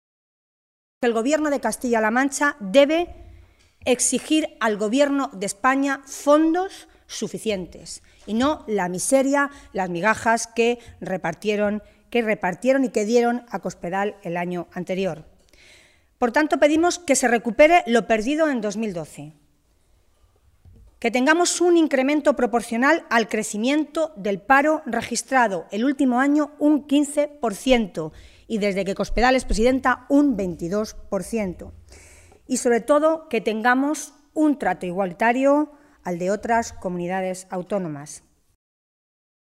Tolón se pronunciaba de esta manera en una comparecencia ante los medios de comunicación esta mañana, en Toledo, en la que pedía que el incremento de los fondos para combatir el paro para este año “fuera, al menos, proporcional al incremento del desempleo en nuestra región, que es del 15 por ciento el último año y del 22 por ciento desde que gobierna Cospedal”.
Cortes de audio de la rueda de prensa